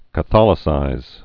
(kə-thŏlĭ-sīz)